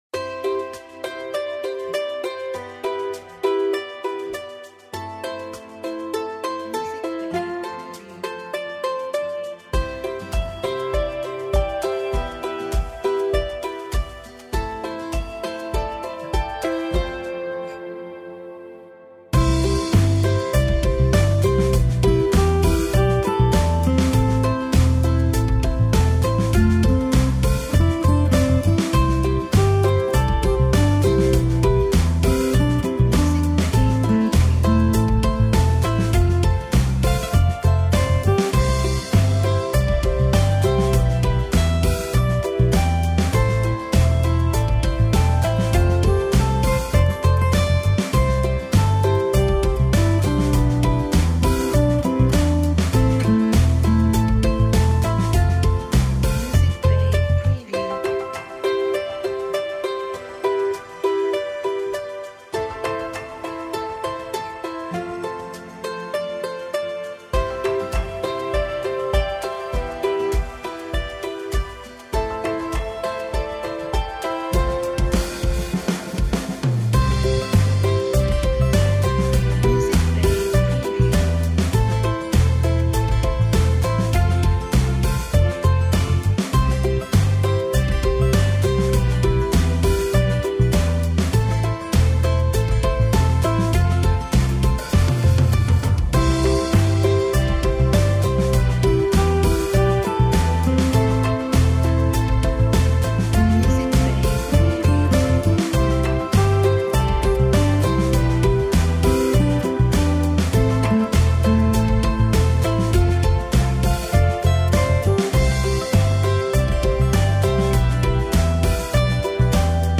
carefree – all happy and joyful background music for videos